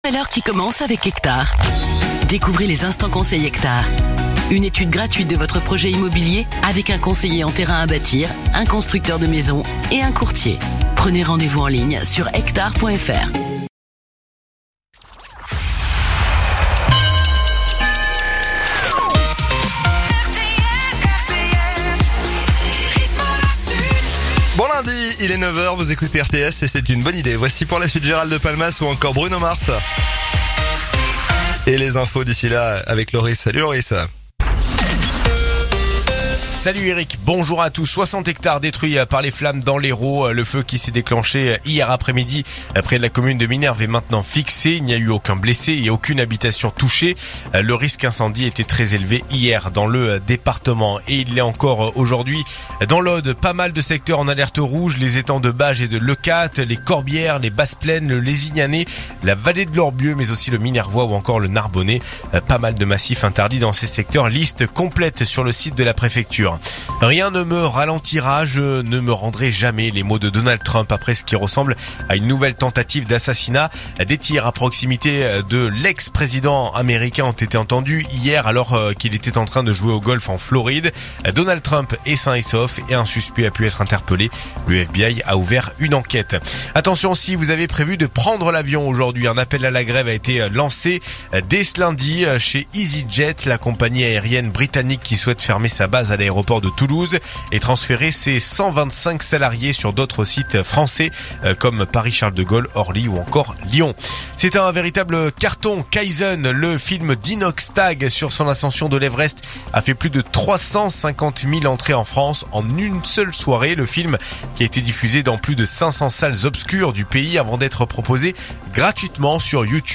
Écoutez les dernières actus de Narbonne en 3 min : faits divers, économie, politique, sport, météo. 7h,7h30,8h,8h30,9h,17h,18h,19h.